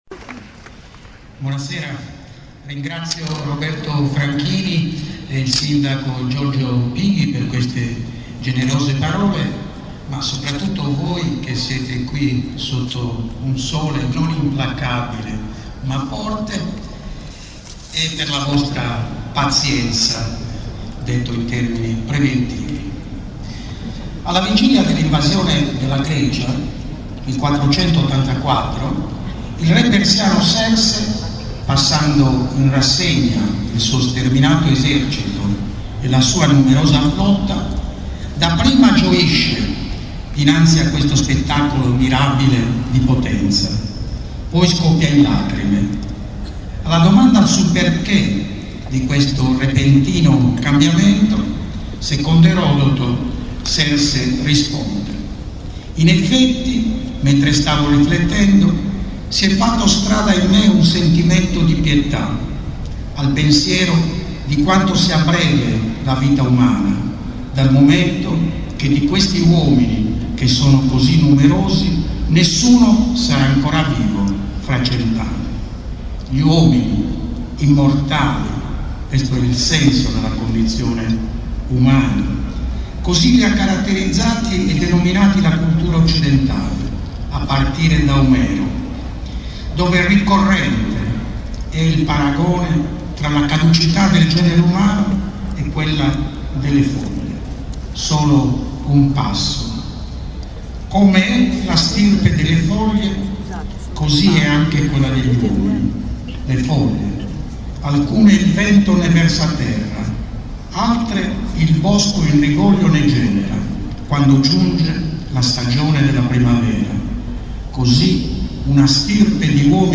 "La condizione umana" Anno 2006 - Piazza Grande - Modena